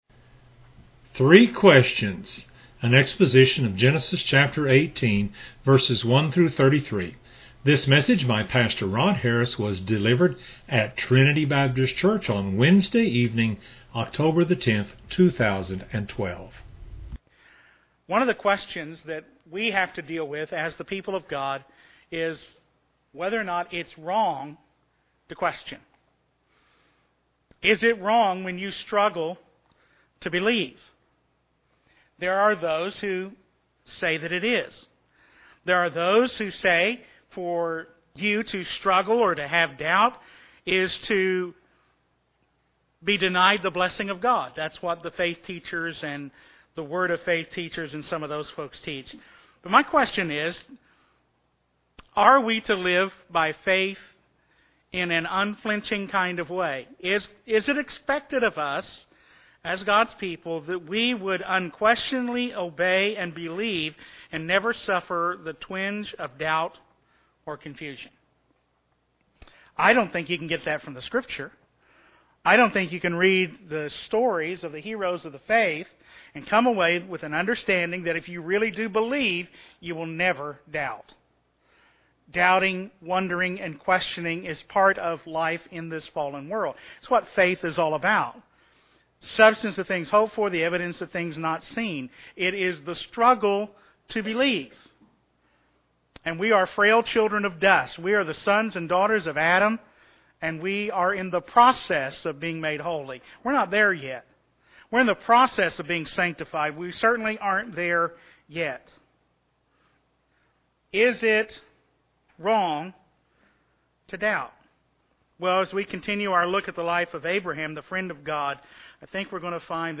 This is an exposition of Genesis 18:1-33.